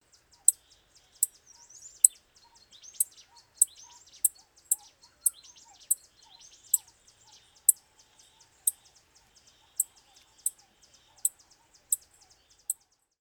Pinzón Cafetalero (Melozone cabanisi)
geo.locationCosta Rica